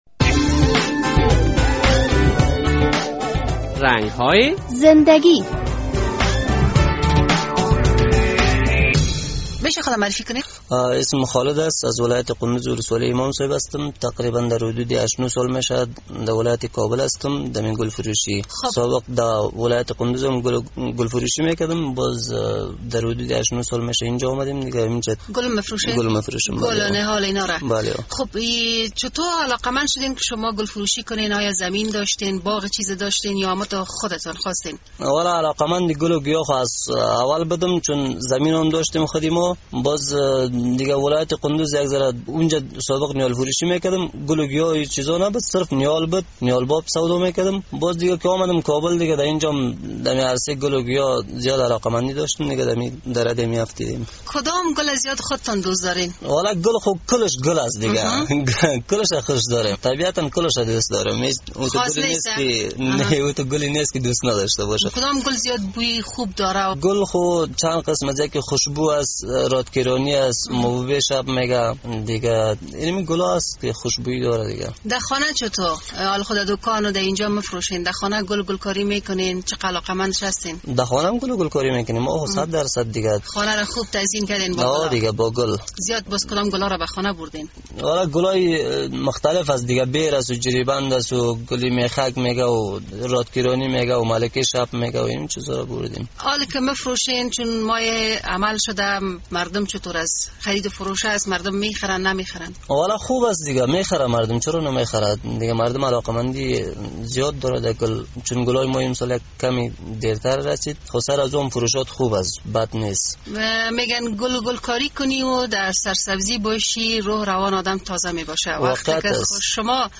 مصاحبه کرده ایم